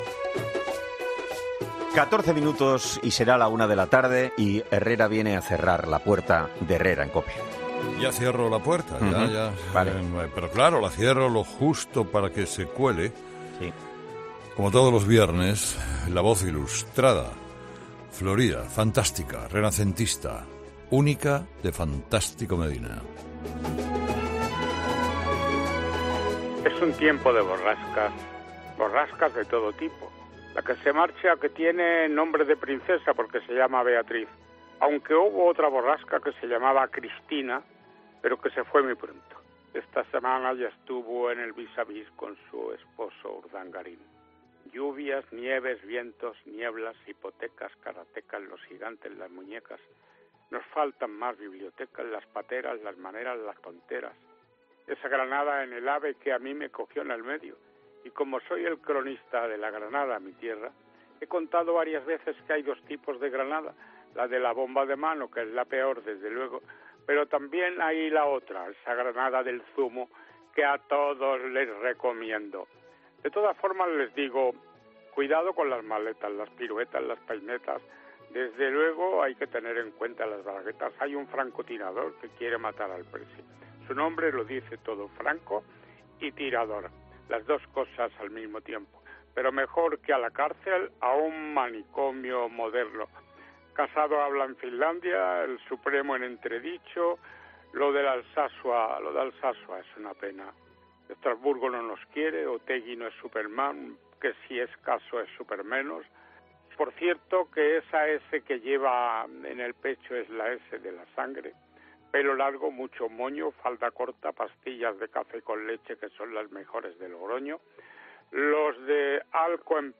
Escucha ahora al maestro Tico Medina en la despedida de ‘Herrera en COPE’ como cada viernes.